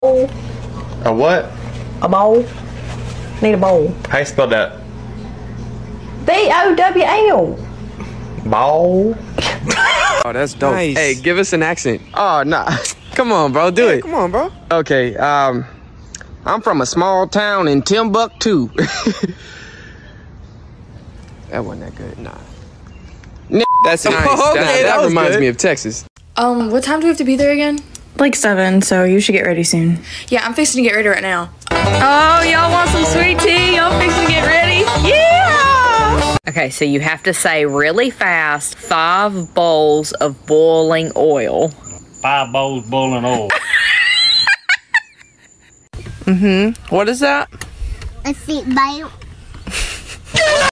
Top 5 Southern Accent of sound effects free download